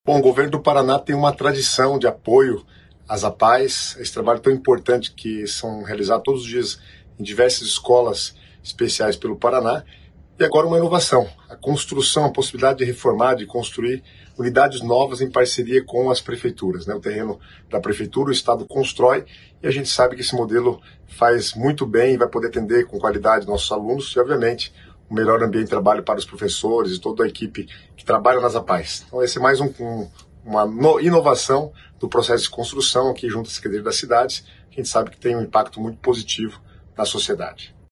Sonora do secretário das Cidades, Guto Silva, sobre o apoio do governo estadual para a construção de novas sedes das Apaes